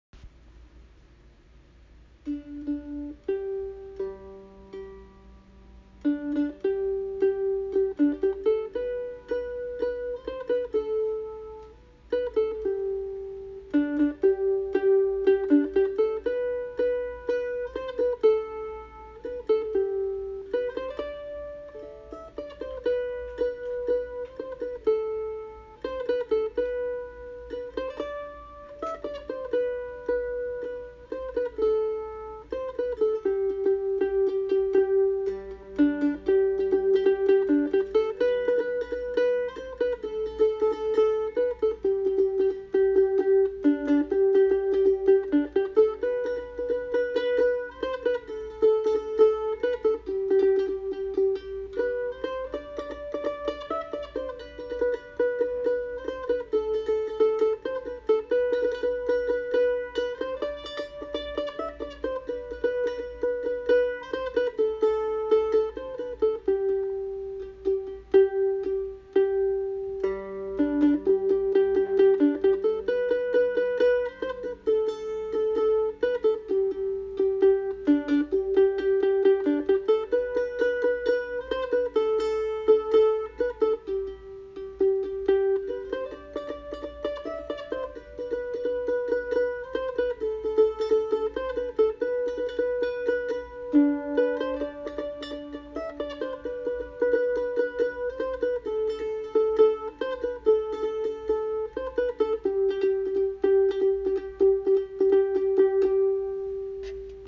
Played on my Ratliff mandolin
Recordingsend-the-light-mandolin-1-1.mp3